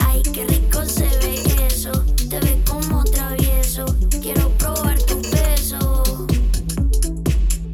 ▼RIME 7.1.4チャンネル設定のサウンド
こちらも参考音源のため、環境によって聞こえ方は異なりますが、上下左右から音が聞こえるイマーシブオーディオの効果を感じていただけたかと思います。